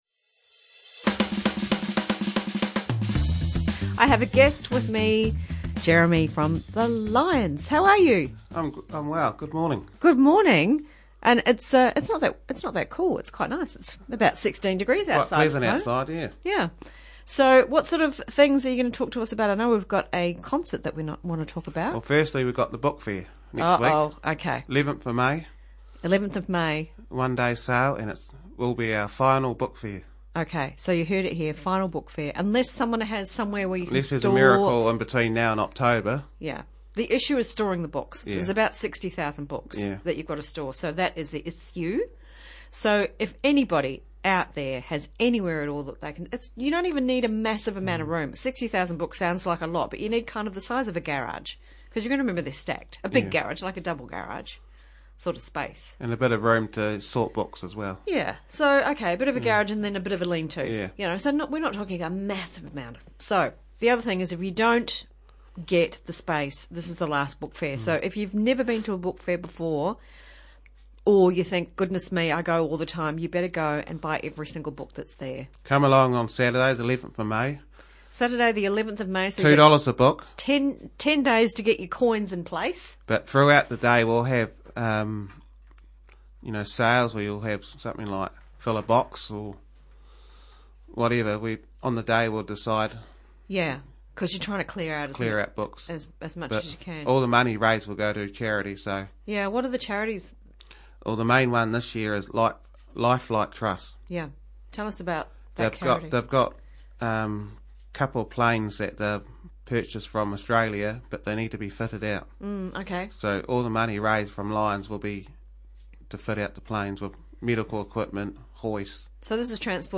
Live show interviews Coast Access Radio - Lions Book Fair - last one EVER! Unless they secure somewhere to store and sort books.
A range of topics and genres from both our live studio and prerecorded shows.